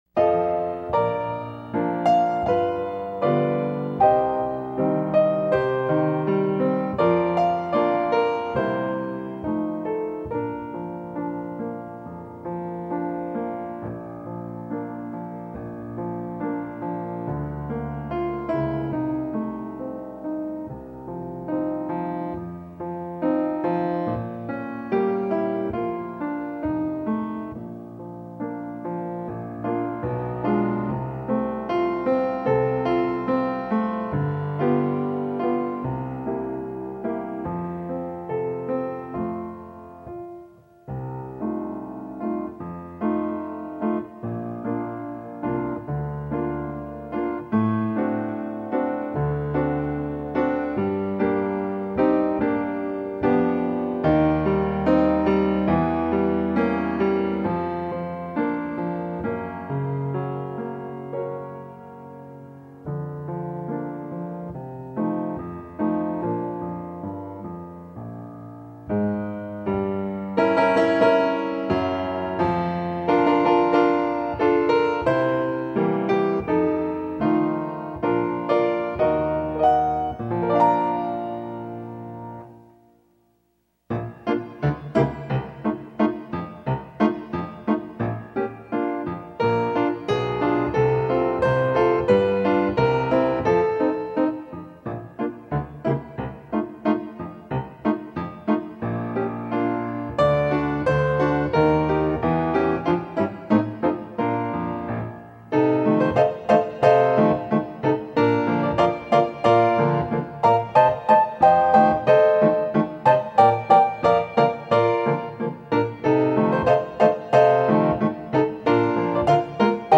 Lyra Trumpet Solo with Piano Accompaniment – Performance Tempo Lyra Trumpet Solo with Piano Accompaniment – Performance Tempo Lyra Trumpet Solo Piano Only – Performance Tempo Lyra Trumpet Solo Piano Only – Performance Tempo